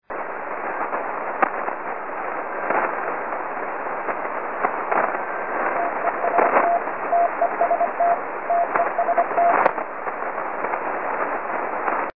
маяк со словом ТЕСТ